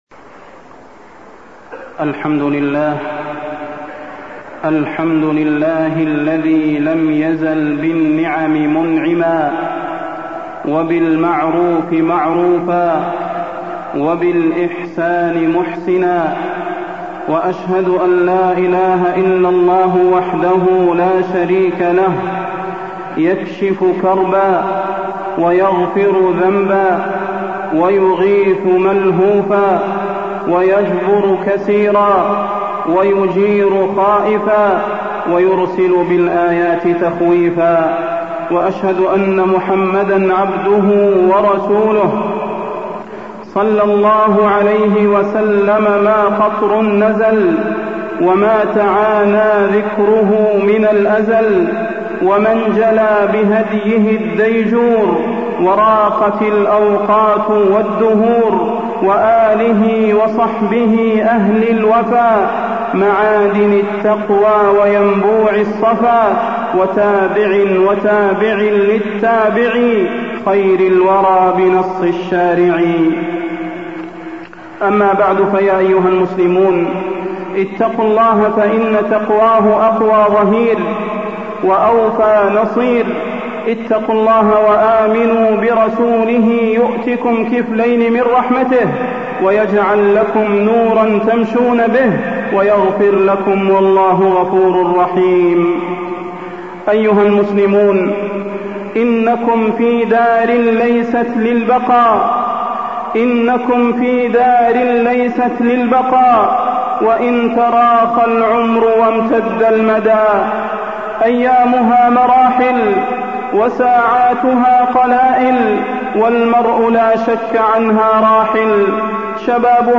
تاريخ النشر ١٣ جمادى الآخرة ١٤٢٥ هـ المكان: المسجد النبوي الشيخ: فضيلة الشيخ د. صلاح بن محمد البدير فضيلة الشيخ د. صلاح بن محمد البدير الموت